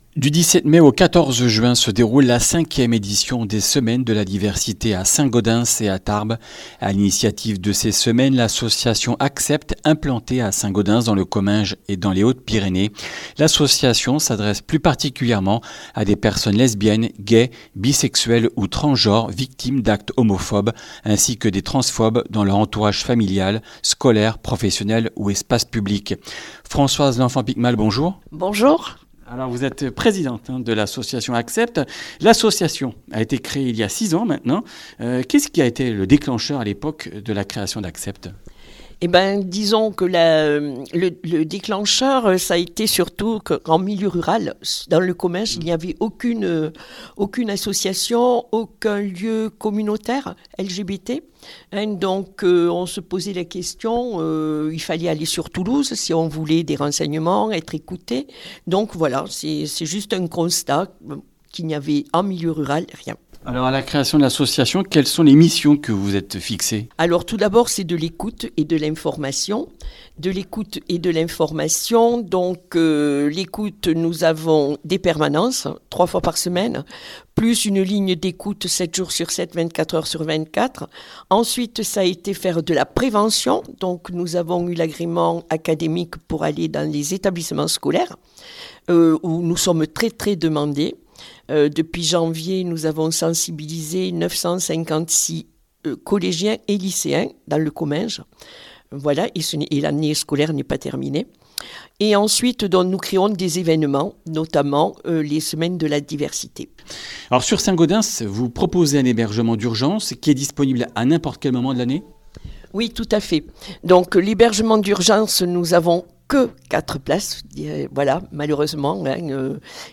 Comminges Interviews du 13 mai